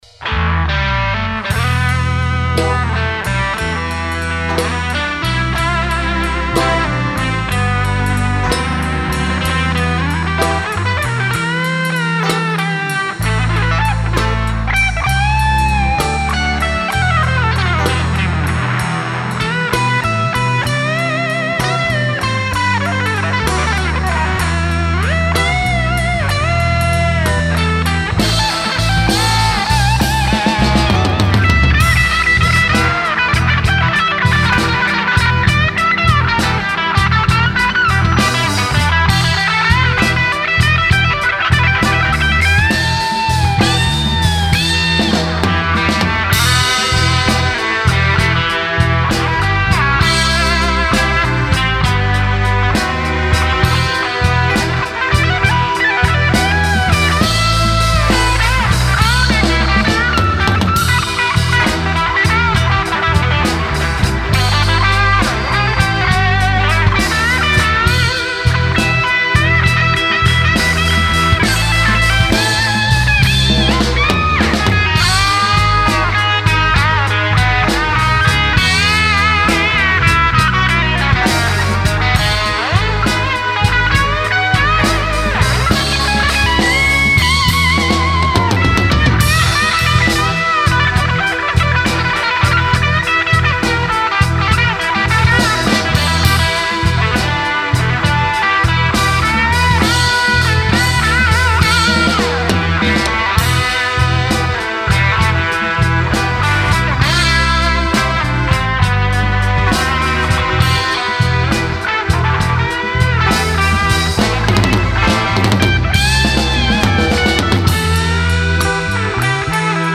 Потому не судите строго-это не студийные вылизанные записи-это как в жизни- по всякому.
Еще один инструментал.